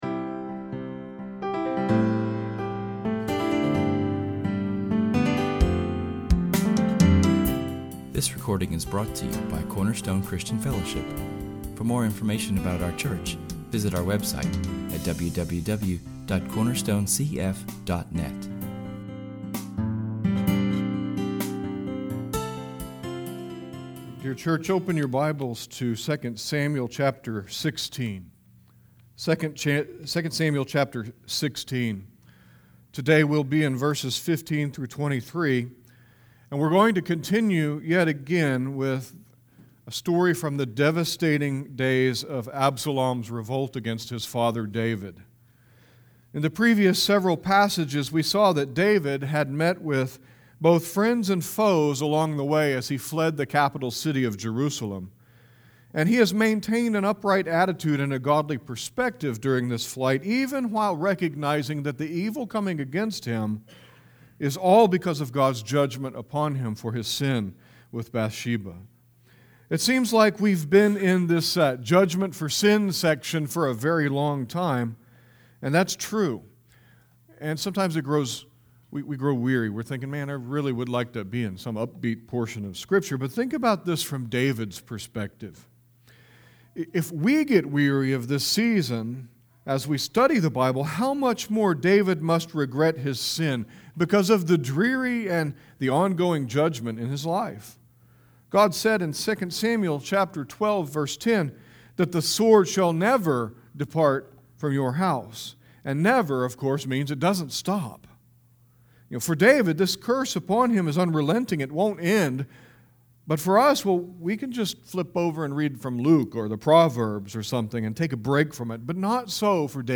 Our sermon is entitled Friends and Traitors and comes from [esvignore]2 Samuel 16:15-23[/esvignore].